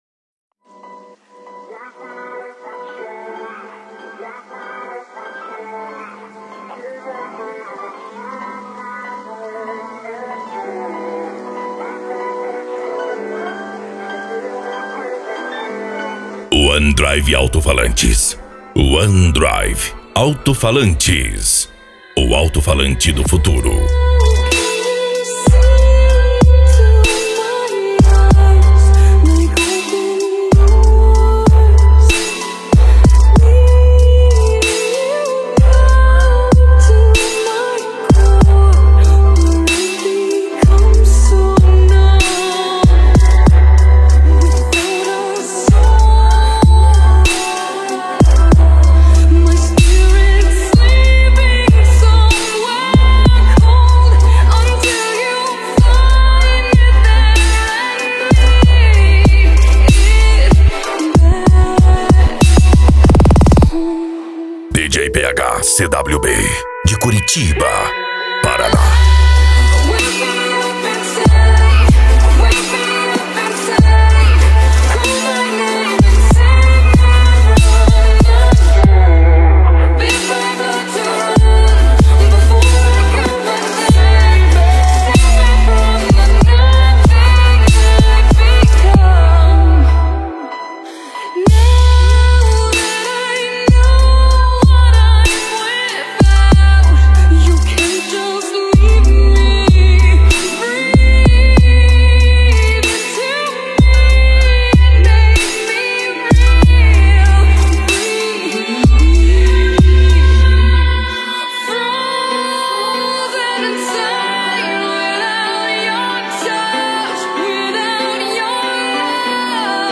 Eletronica
Funk
PANCADÃO
SERTANEJO